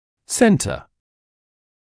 [‘sentə][‘сэнтэ]центр, середина; ставить по центру, располагать(ся) по центру (US center)